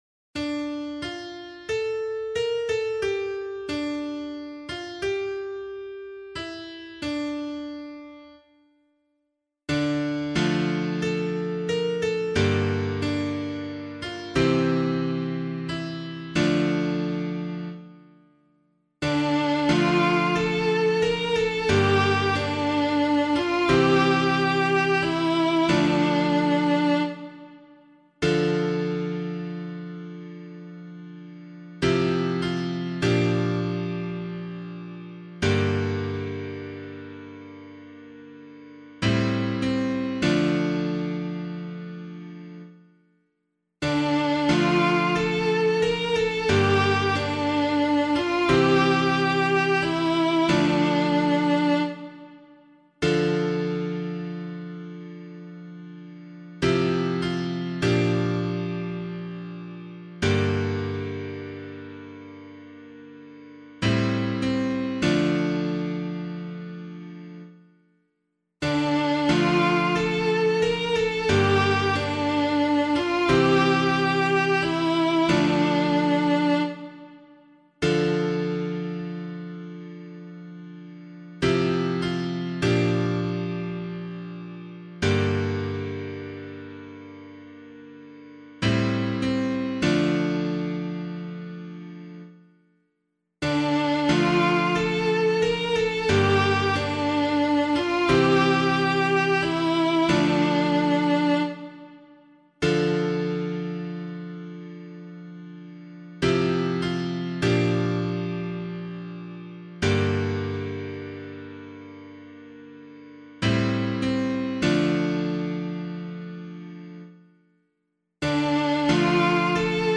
327 Assumption Day Psalm [Abbey - LiturgyShare + Meinrad 1] - piano.mp3